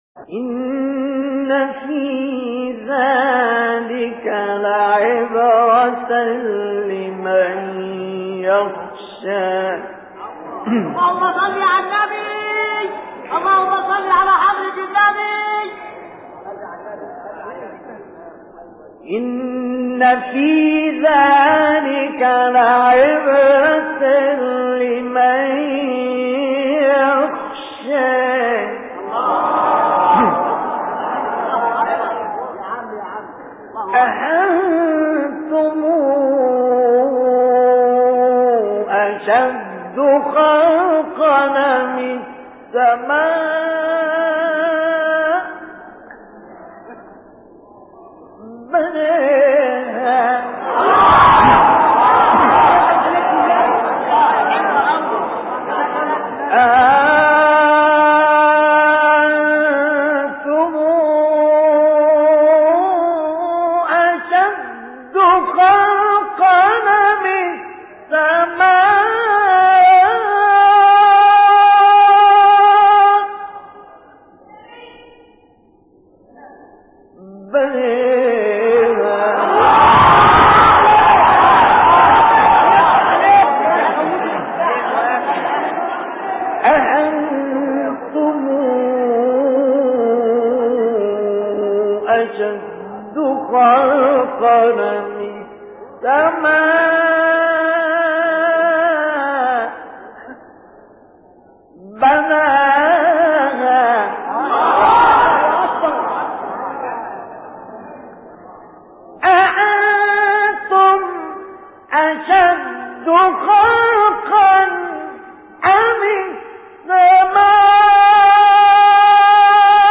حیات اعلی :: دریافت 6- نازعات = بوستان تلاوت 98